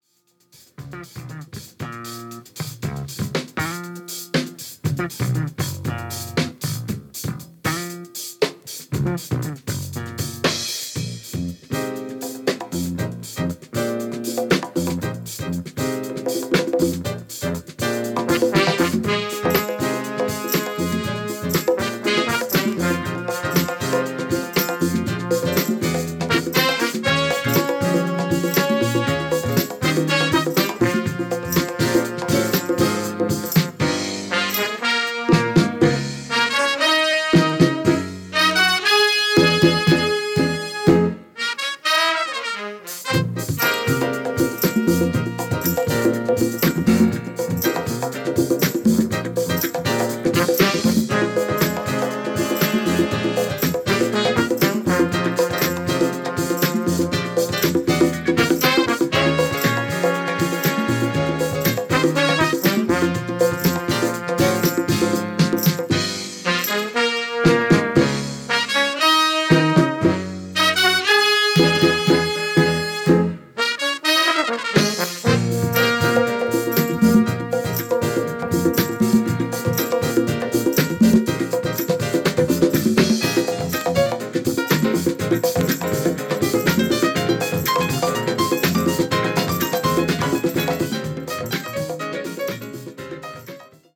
この頃はFunkyですね～。